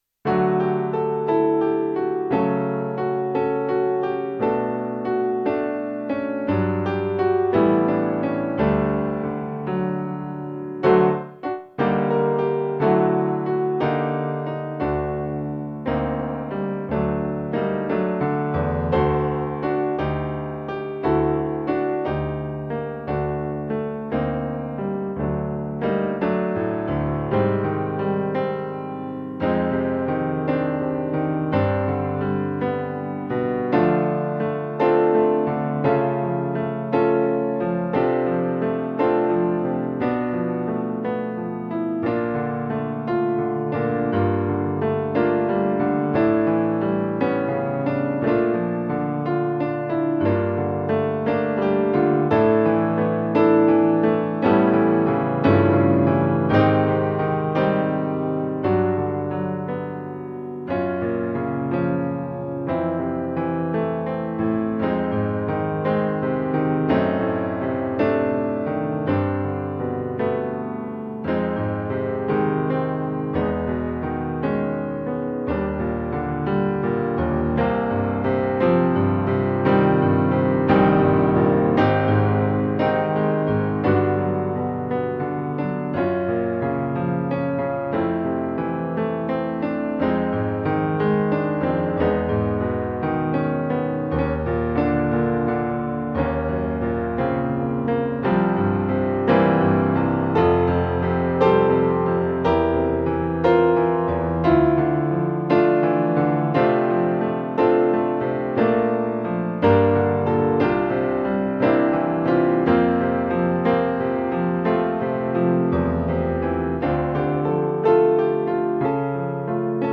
OGG Piano only